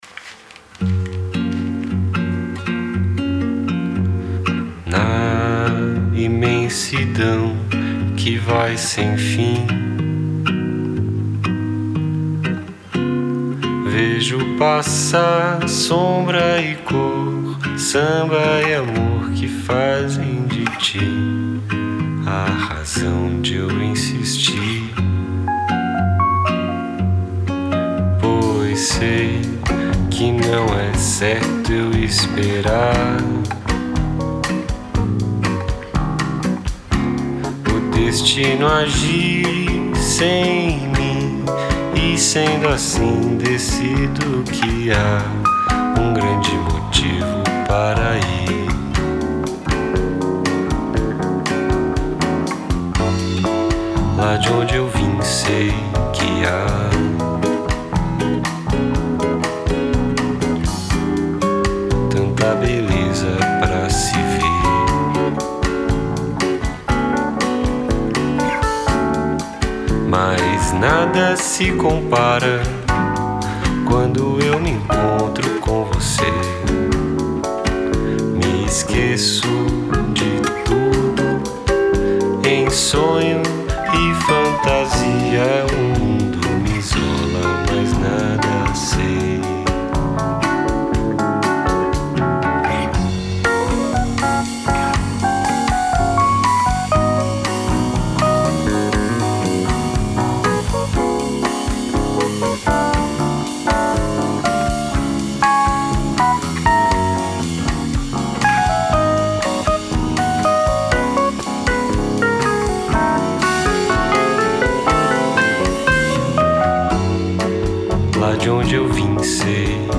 EstiloBossa Nova